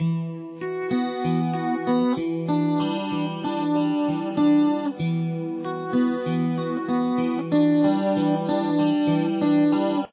Here are the above 2 measures looped at a slower tempo: